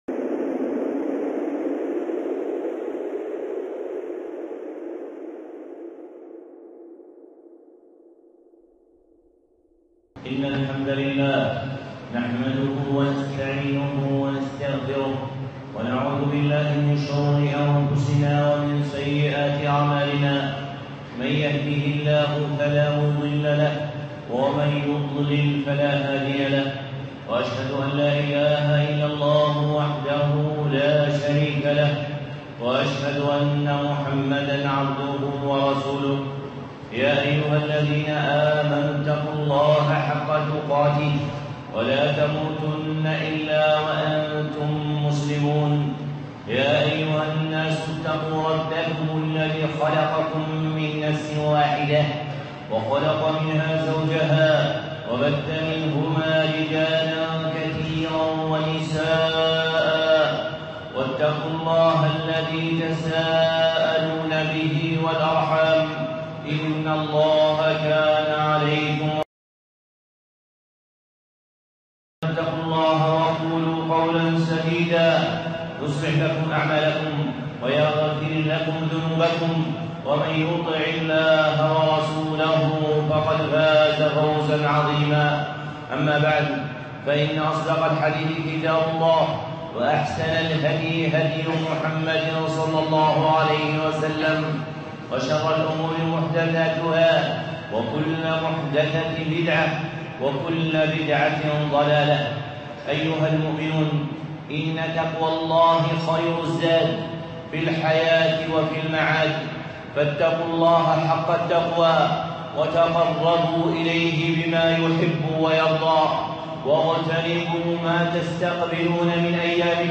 خطبة (استقبال رمضان)